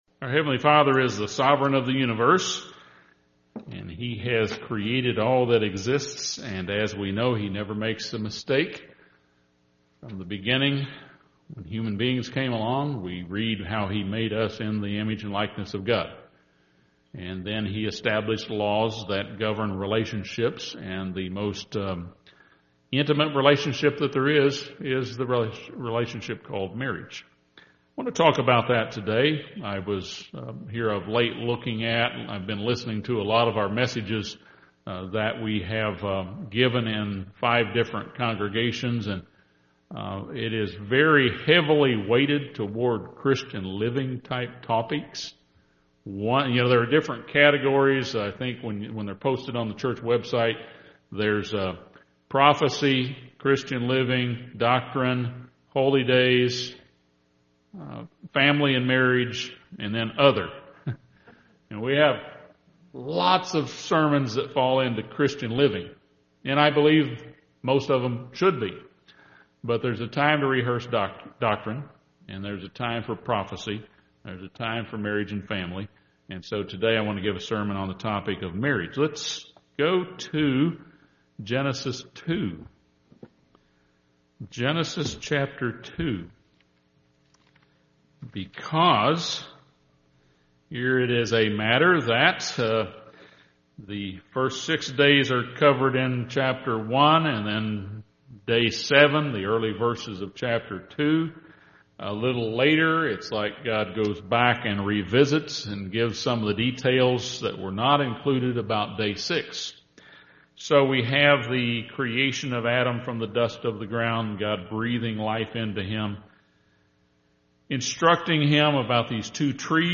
This sermon expounds on 1 Corinthians 13 as a guide for strengthening love between a husband and wife.